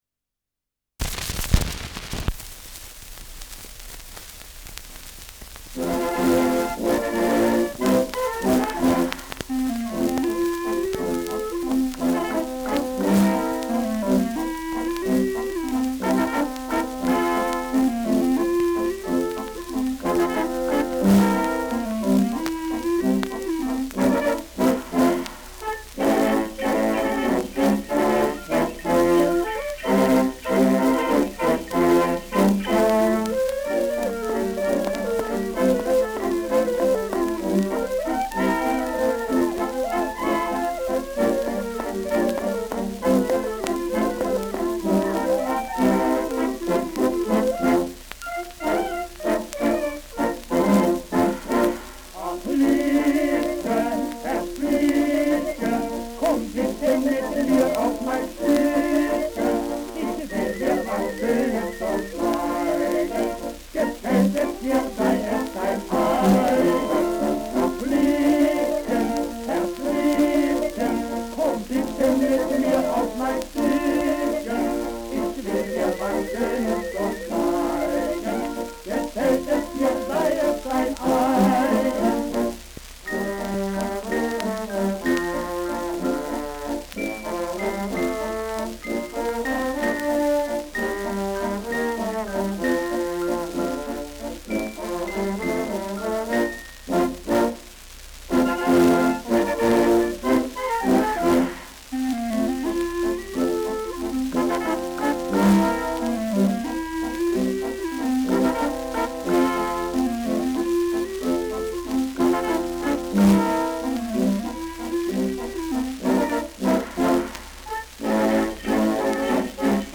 Schellackplatte
Tonrille: Kratzer 3 Uhr Stärker : Berieb 11 Uhr Stärker
präsentes Rauschen